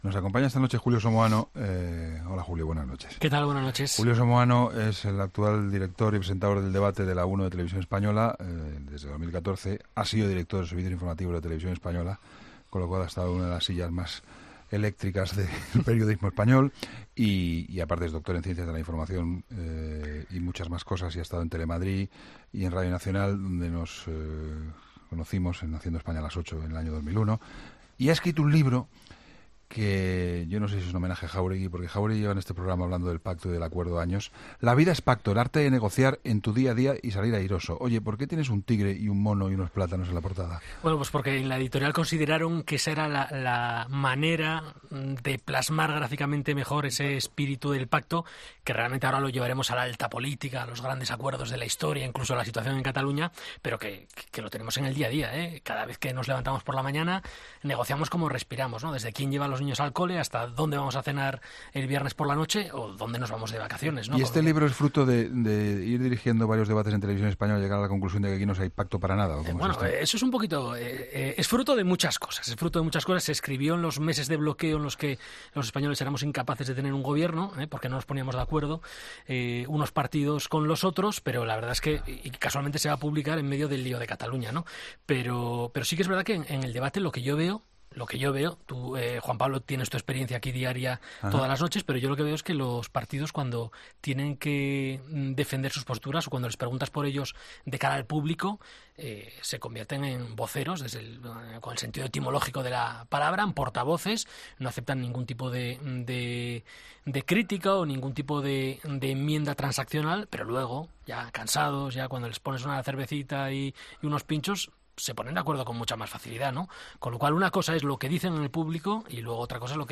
Entrevistas en La Linterna
El periodista Julio Somoano ha visitado 'La Linterna' para presentar su libro 'La vida es pacto'